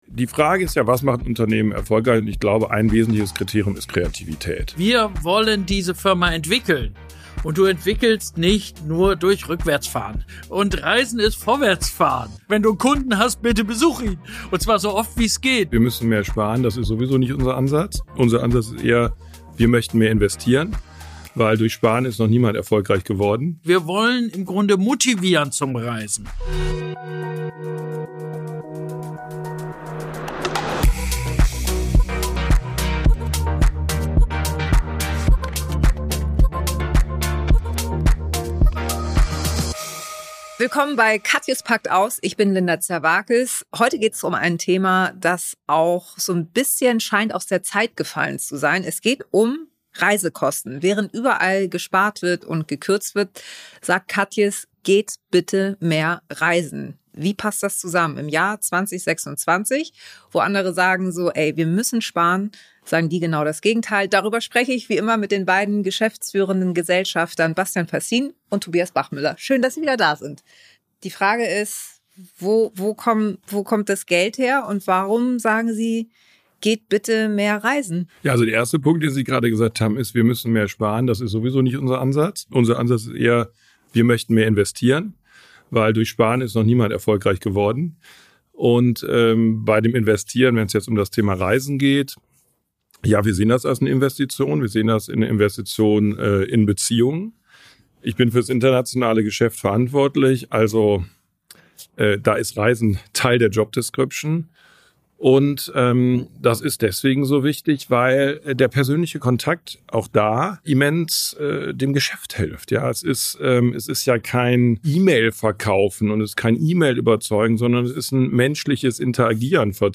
Eine ehrliche, unaufgeregte Diskussion über Investieren statt Kürzen, Vertrauen statt Kontrolle und darüber, warum Inspiration selten am Schreibtisch entsteht, sondern oft unterwegs.